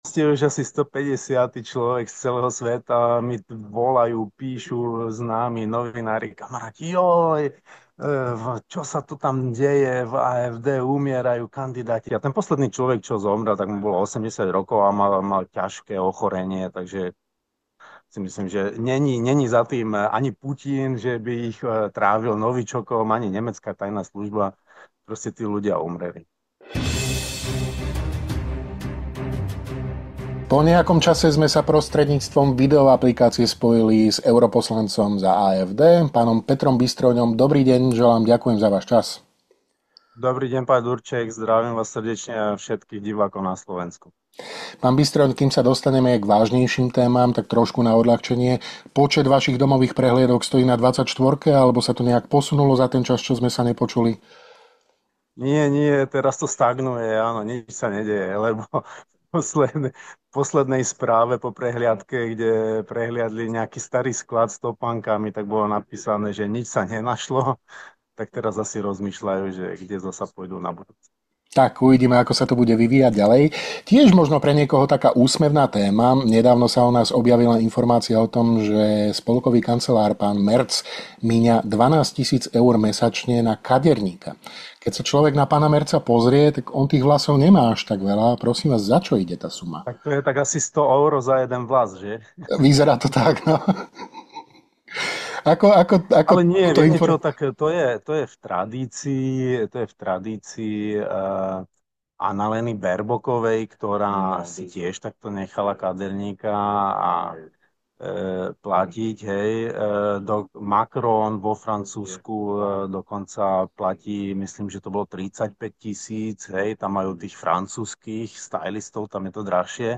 Europoslanec za AfD Peter Bystroň v rozhovore pre HS opäť upozorňuje na politické kontroverzie v európskej politike